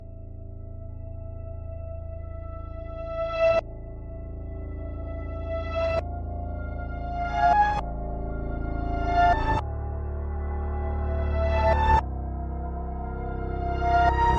阴森的反转钢琴循环 100
描述：非常阴森恐怖 idk what key but its something .
Tag: 100 bpm Trap Loops Piano Loops 2.42 MB wav Key : Unknown